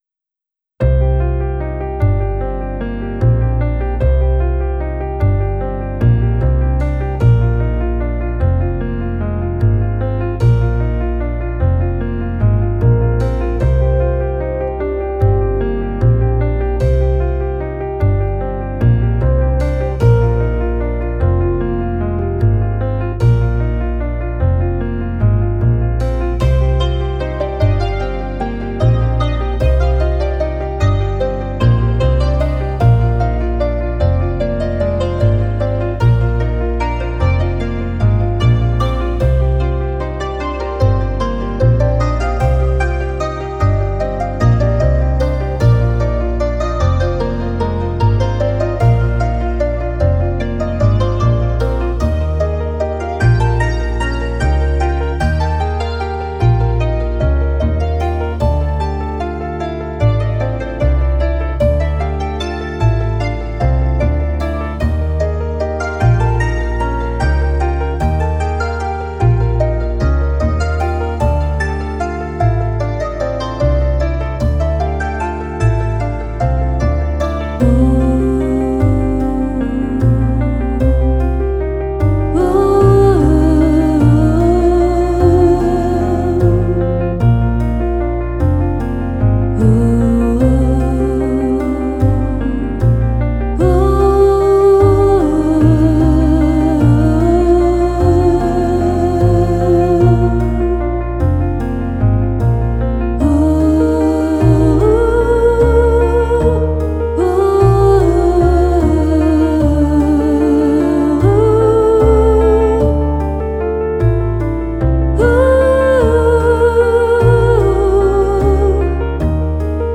Genre this time is folk music with a Nordic sound and feel. I use a mix of traditional instruments, synth, and voice. The rhythm is slow (75 bpm) and strong but without heavy drum beating. A folky tune that would be great to grab each other in the hands and dance in a large circle.
This one is a completely new version with better instruments and better mixing and mastering.